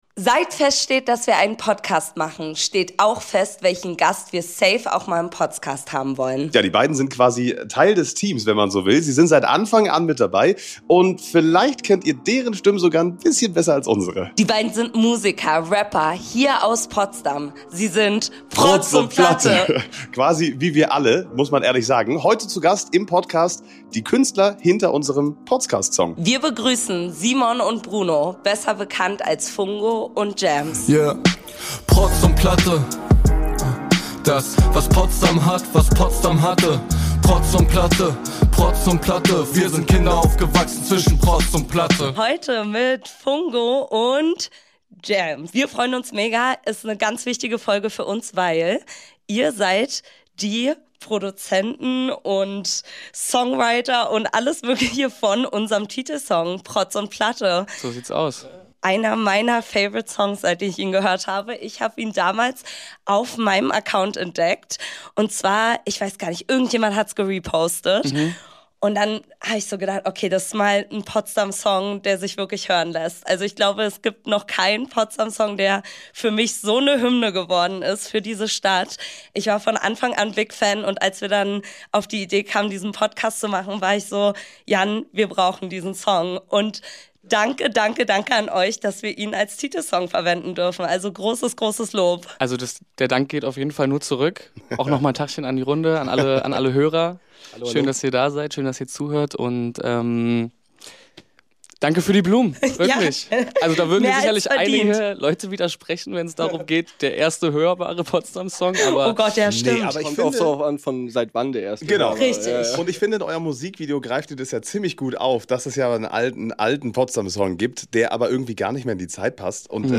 Wir haben die beiden in ihrem Studio im Rechenzentrum besucht: Wie kam es zu „Protz & Platte“? Hatten die beiden Rapper eigentlich schon mal Beef? Und wie versteht sich Potsdams Musikszene untereinander?